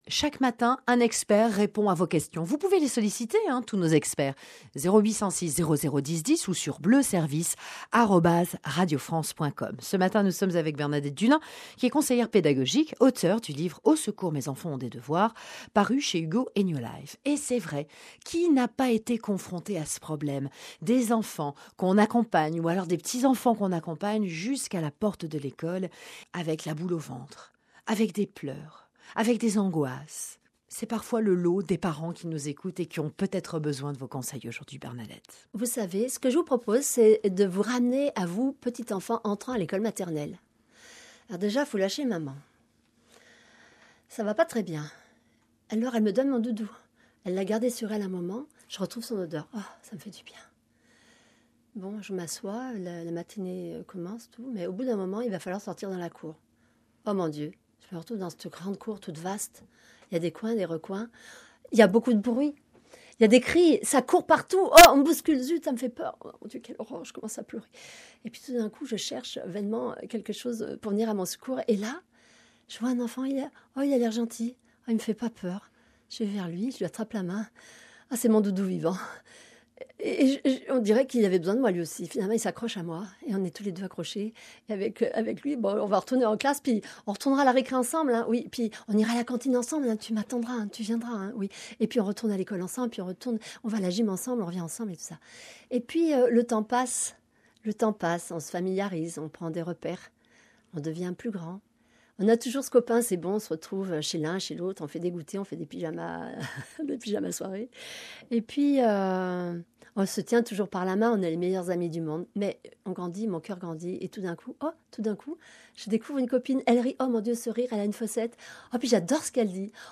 France_bleu_savoie_Chagrin-d-amitie.MP3